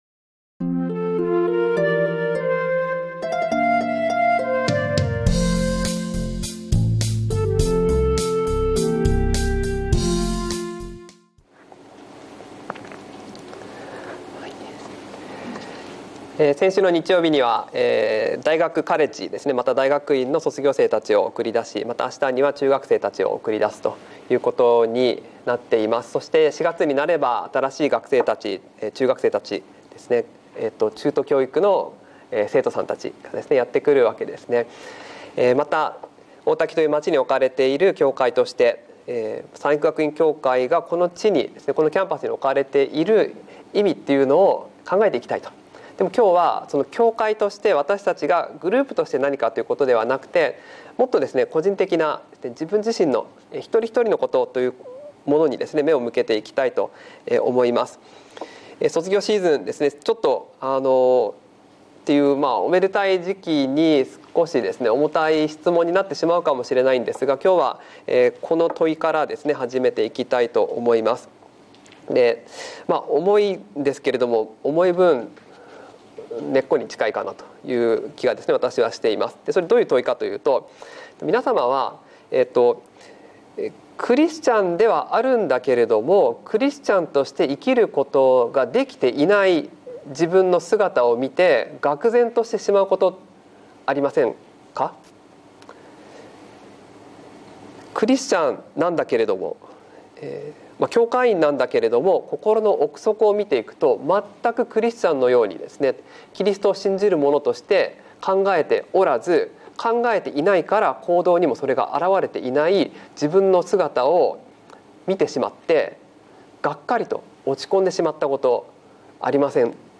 聖書の言葉：ローマの信徒への手紙８章５～11 節 収録：セブンスデー・アドベンチスト三育学院キリスト教会